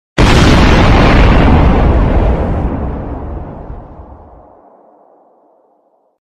Giant Explosion Sound Effect Download: Instant Soundboard Button
Play and download the Giant Explosion sound effect buttons instantly!